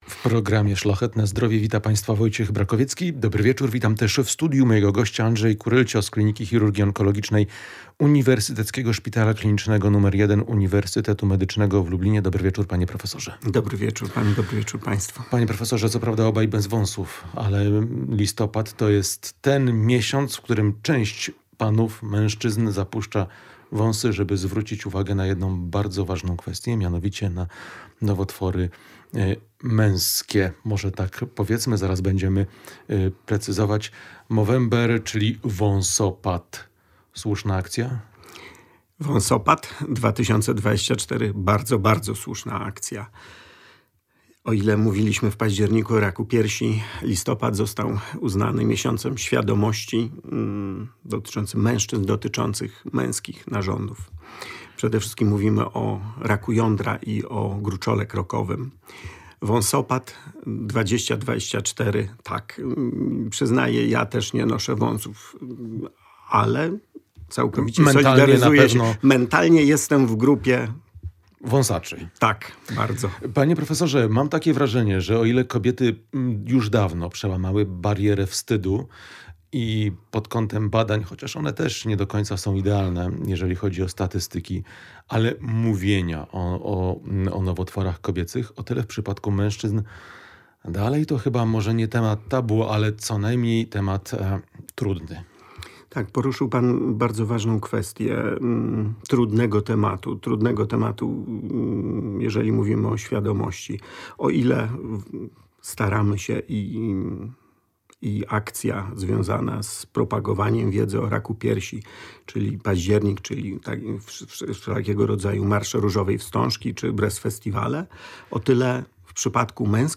Jak powinna wyglądać profilaktyka, samokontrola, diagnostyka i leczenie – o tym w rozmowie ze specjalistą.